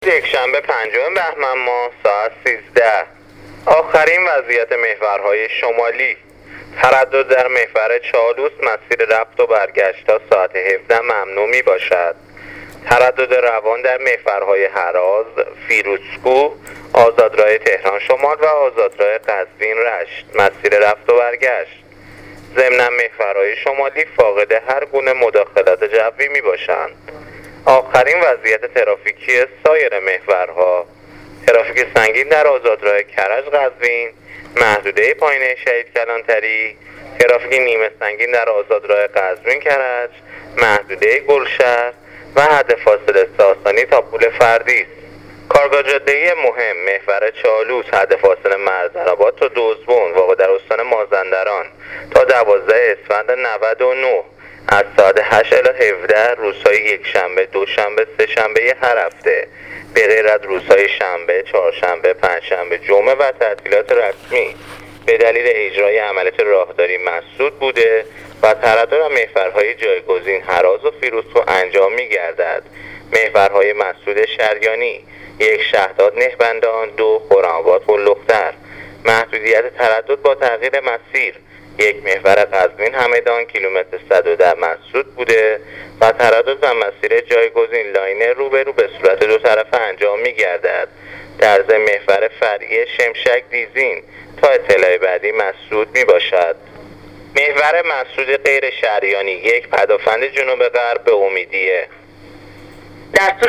گزارش رادیو اینترنتی از آخرین وضعیت ترافیکی جاده‌ها تا ساعت ۱۳ پنجم بهمن